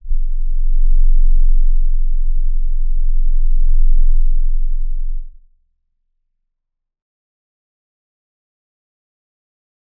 G_Crystal-F0-f.wav